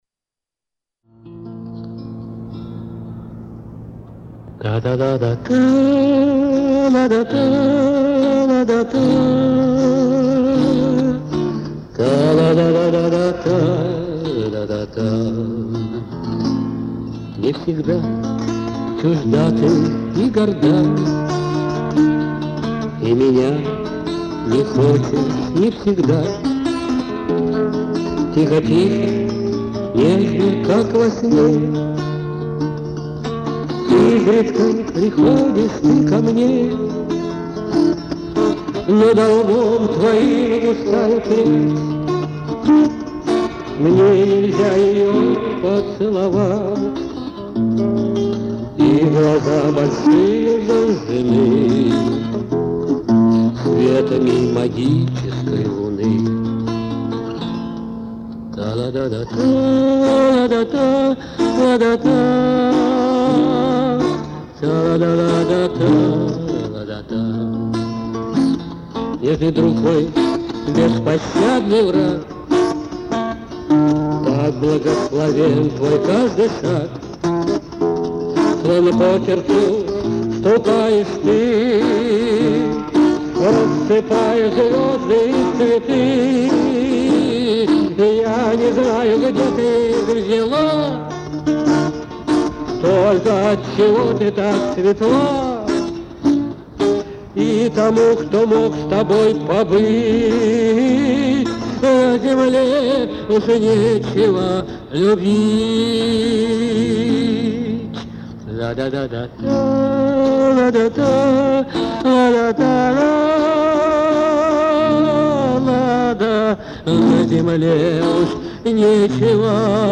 вокал, гитара
Романс